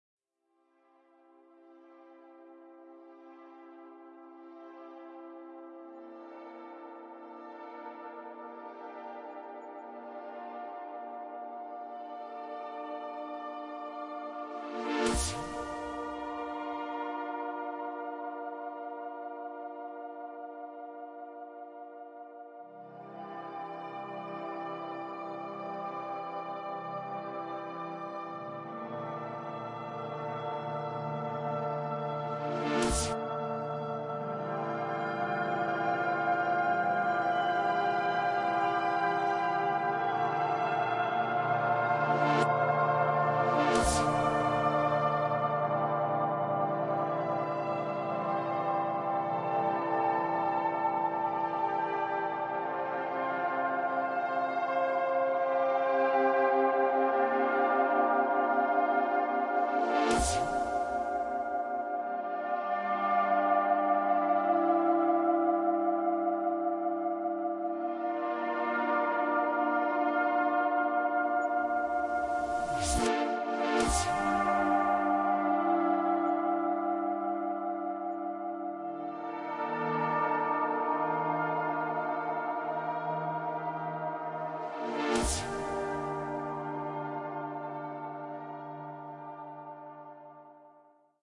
标签： ambient background music
声道立体声